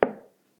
bounce2.ogg